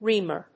/ˈrimɝ(米国英語), ˈri:mɜ:(英国英語)/